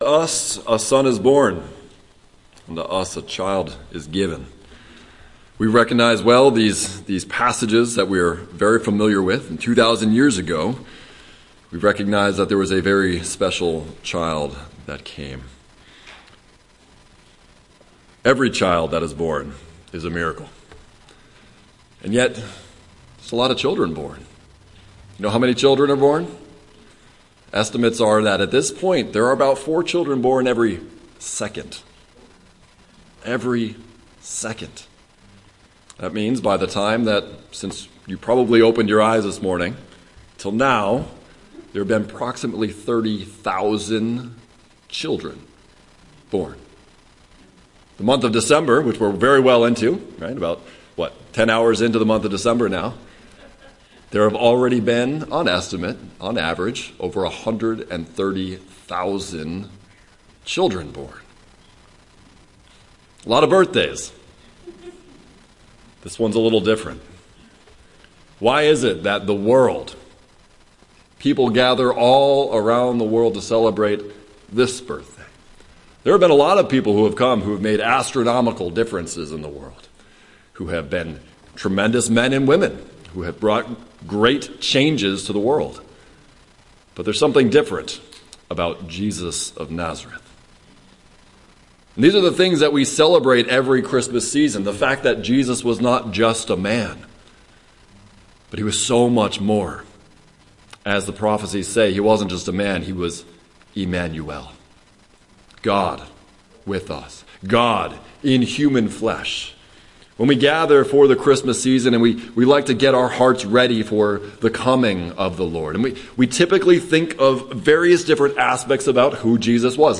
Luke: Christmas through the Disciples Eyes - Jesus as God - Waynesboro Bible Church